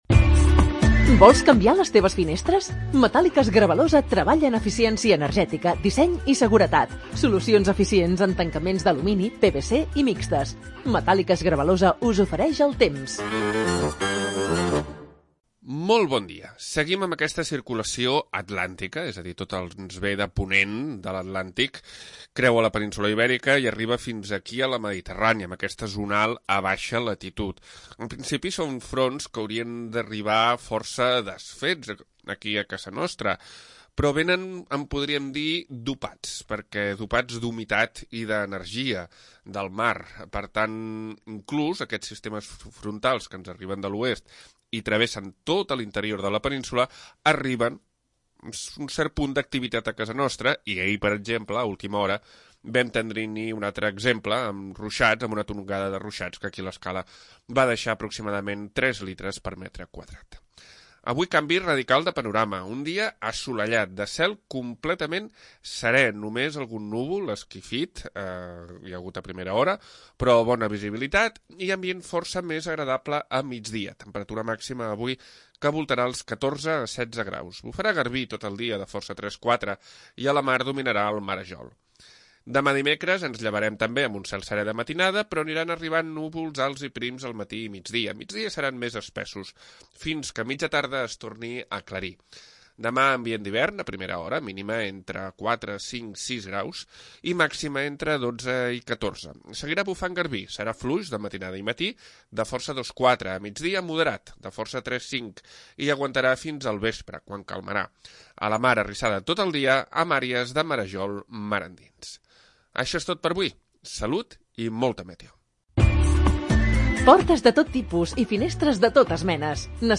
Previsió meteorològica 3 de febrer de 2026